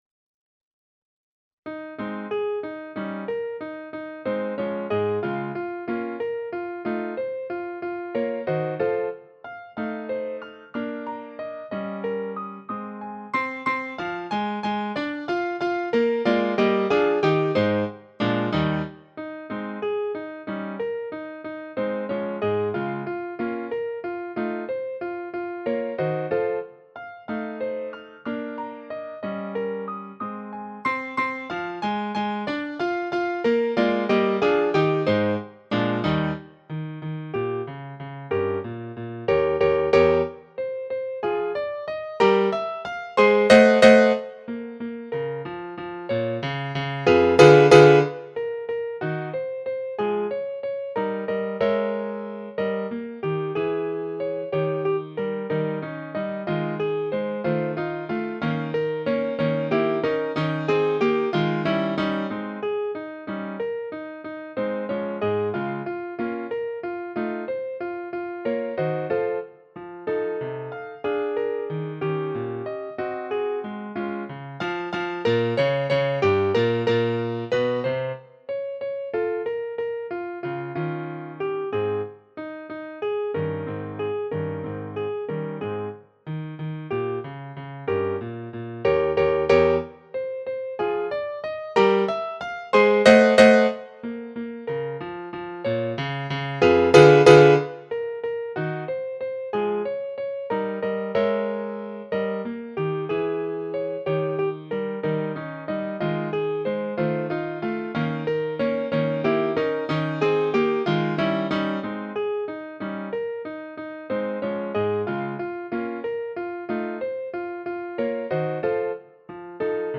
Menuet en la bémol majeur, réduction pour piano
Avec addition d'un petit trio en "mineur".